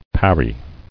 [par·ry]